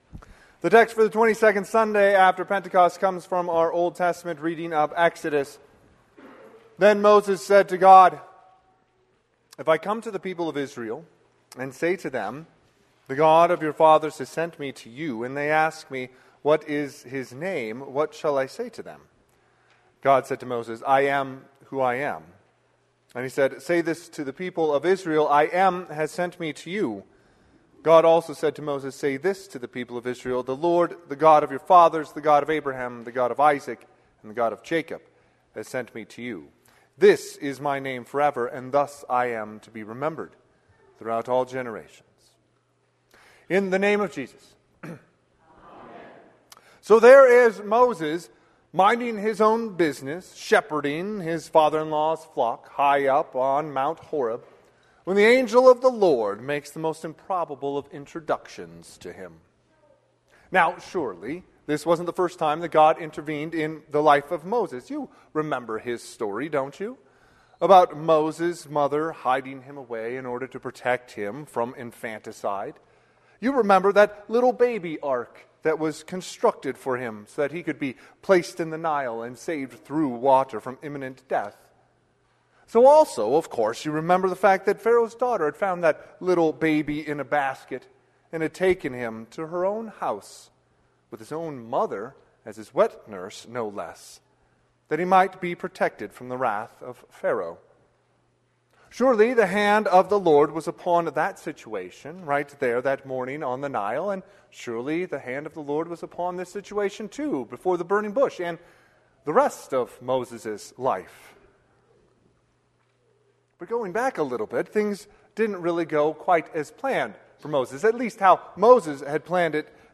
Sermon - 11/9/2025 - Wheat Ridge Evangelical Lutheran Church, Wheat Ridge, Colorado
Twenty-Second Sunday after Pentecost